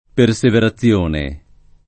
perseverazione [ per S evera ZZL1 ne ] s. f.